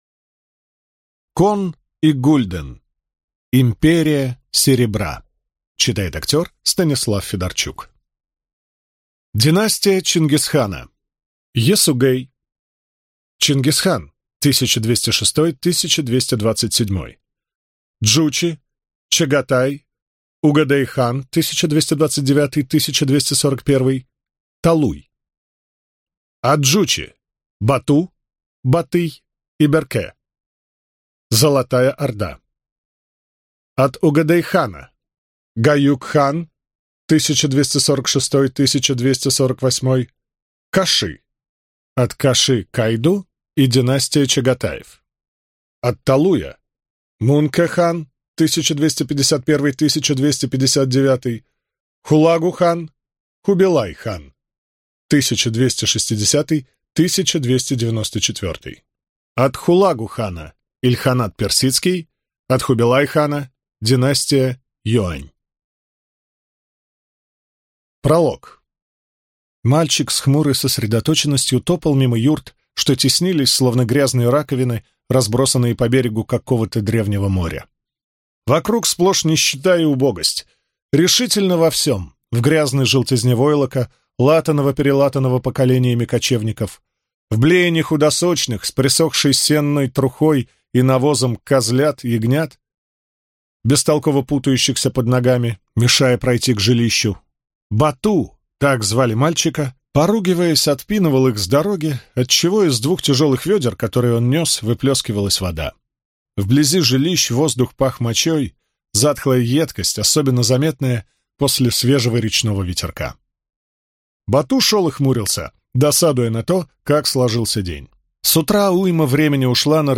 Аудиокнига Империя серебра | Библиотека аудиокниг